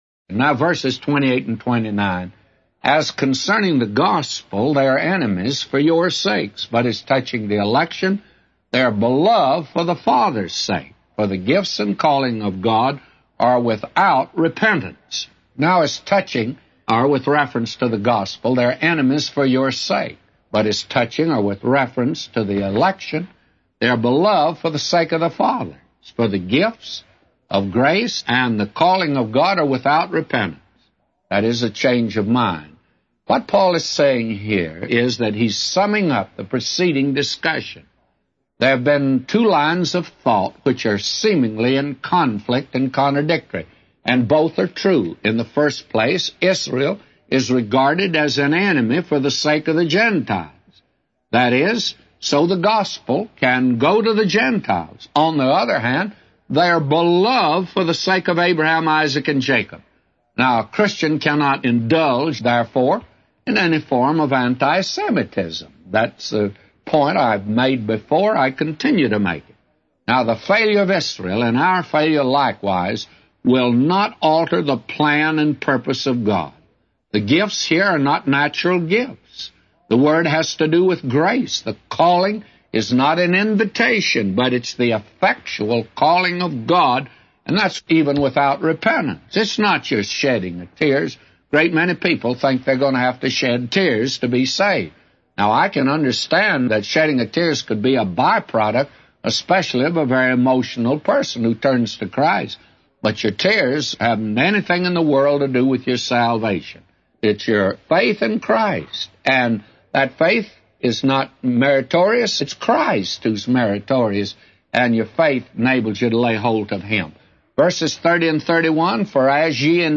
A Commentary By J Vernon MCgee For Romans 11:28-36